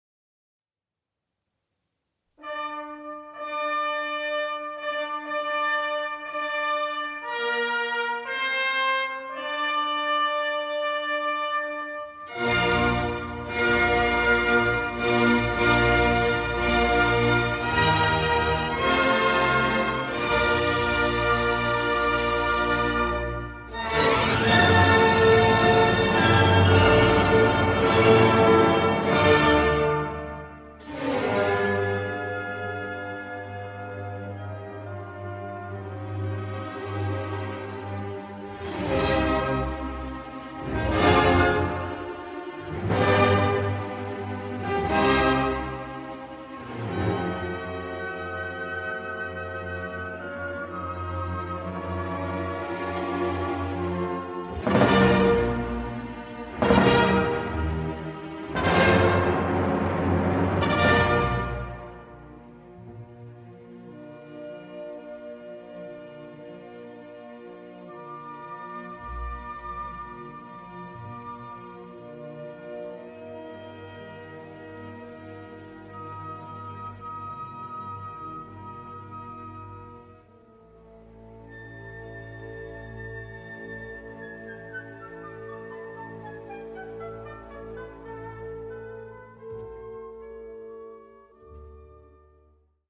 Schumann1szimfonia.mp3